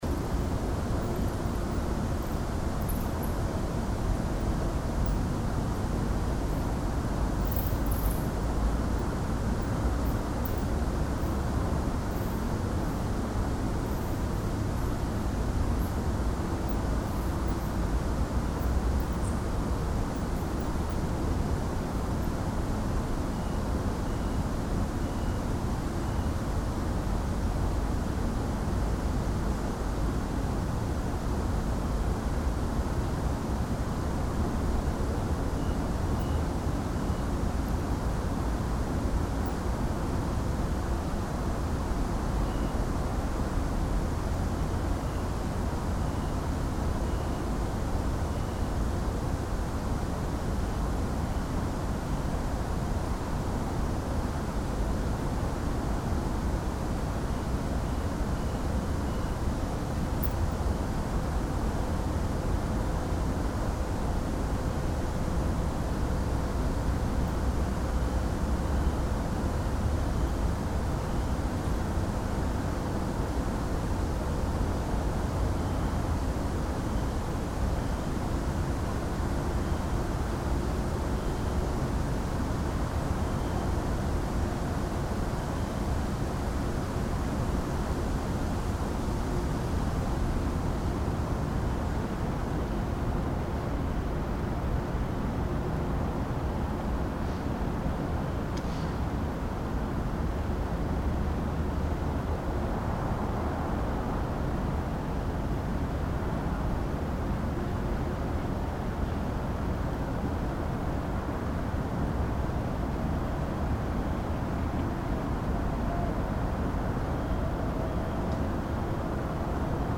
the eight recordings that constitute "soundmap vienna" originate partly from locations inside the city that bear an everyday relationship with me and partly have been "discovered" during a few occasional strolls. what they have in common is that they don’t bear an apparent reference to vienna, neither acoustically nor visually – no fiakers and no vienna boys choir, sorry for that – and that most of them were taken at unusual times during the day. these recordings were left unprocessed and subsequently arranged into a 19-minute long collage. the second, more experimental collage was composed out of midi-data and soundfiles that have been extracted via specific software from the photographs taken at the recording sites.
Prater_Hauptallee.mp3